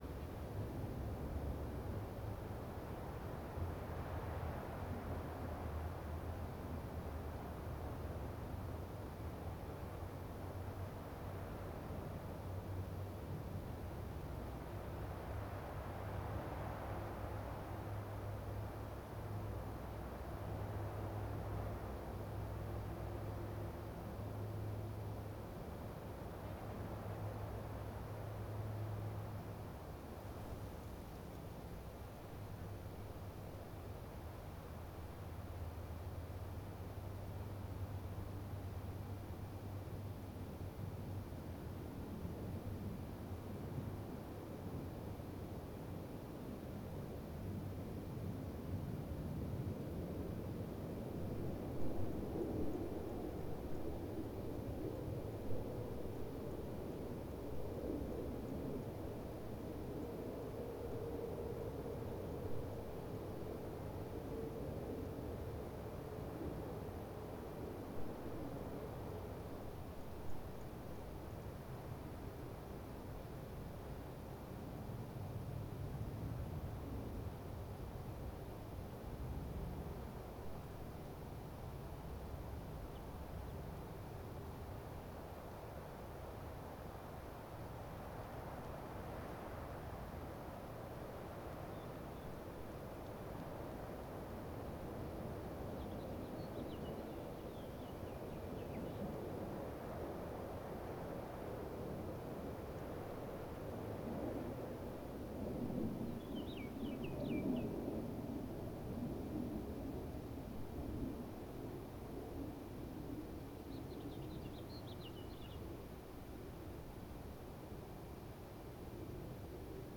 Desert_Day_Atmosphere.R.wav